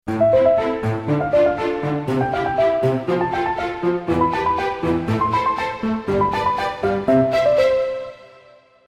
Voici une marche harmonique montante